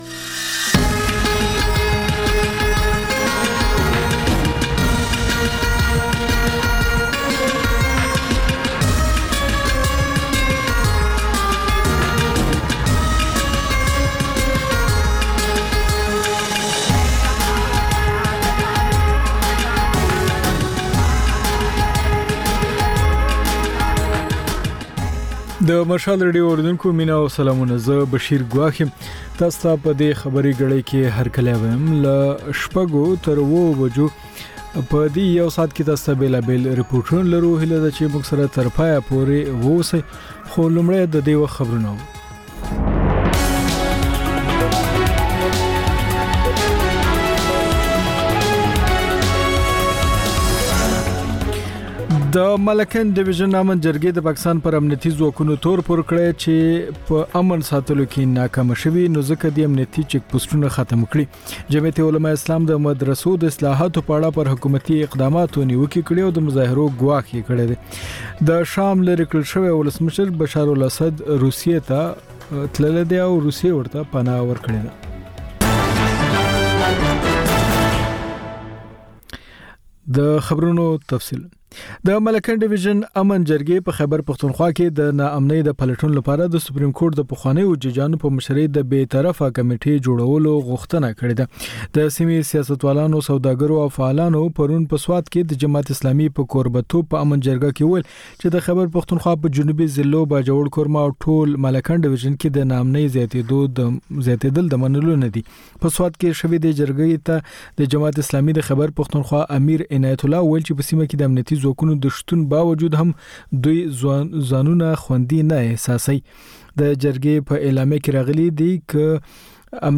د مشال راډیو د ۱۴ ساعته خپرونو دویمه او وروستۍ خبري ګړۍ. په دې خپرونه کې تر خبرونو وروسته بېلا بېل سیمه ییز او نړیوال رپورټونه، شننې، مرکې، کلتوري او ټولنیز رپورټونه خپرېږي.